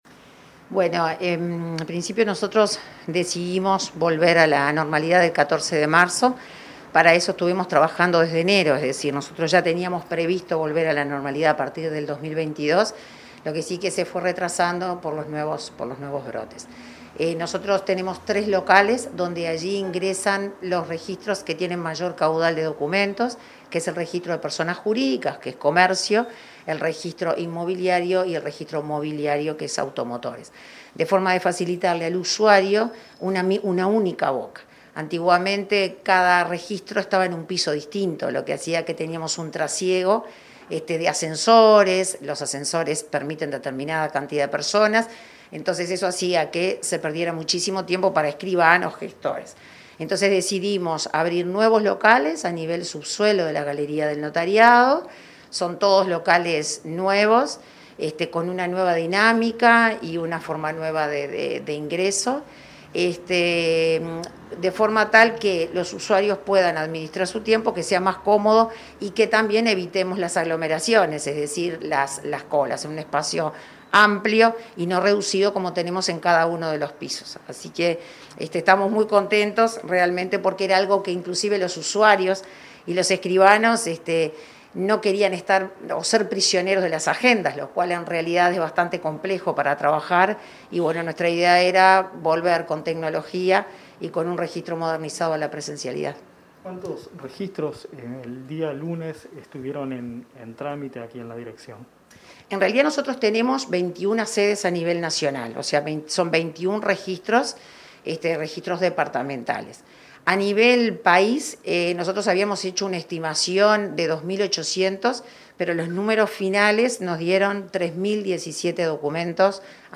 Entrevista a la directora general de Registros
La directora general de Registros, Daniella Pena, dialogó con Comunicación Presidencial sobre la apertura de locales y nuevos procedimientos